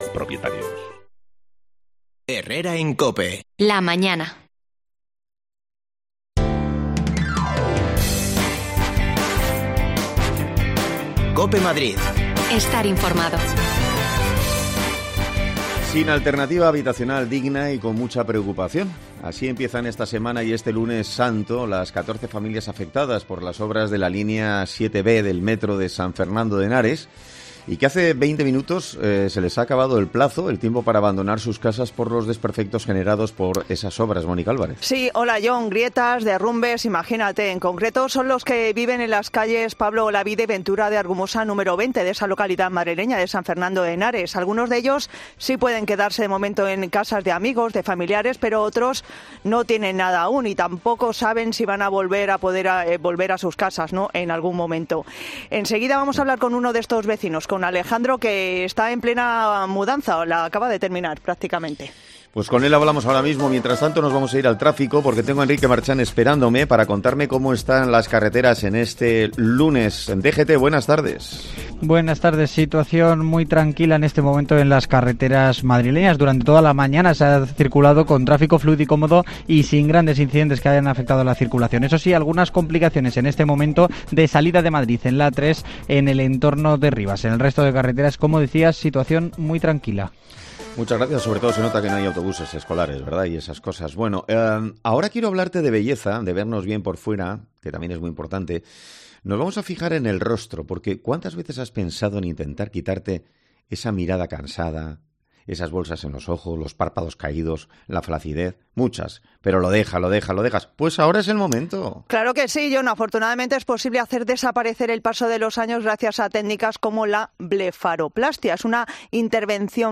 Hablamos con ellos